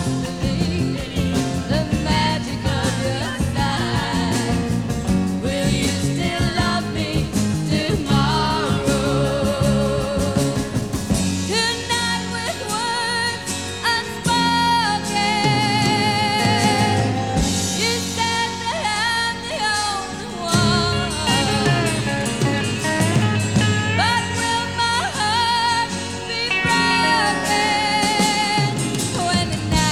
Жанр: Поп музыка / Рок / Фолк / Кантри